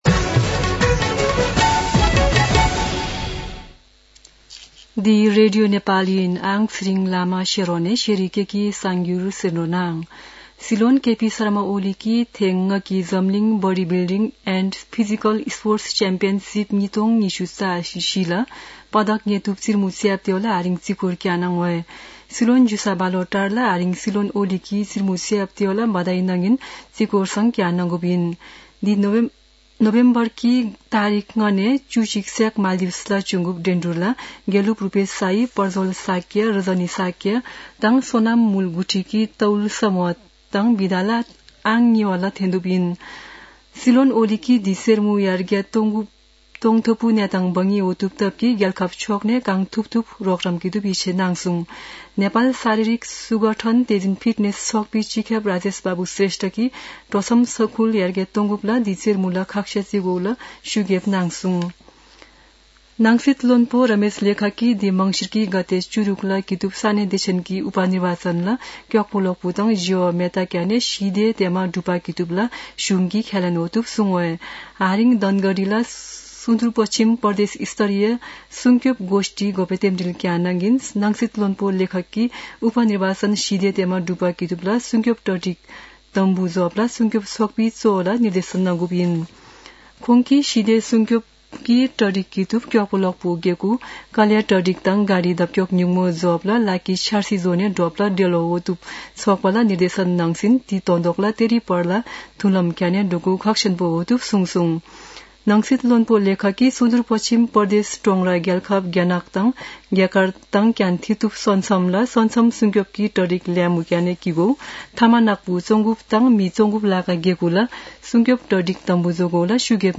शेर्पा भाषाको समाचार : ५ मंसिर , २०८१
4-pm-Sherpa-news-.mp3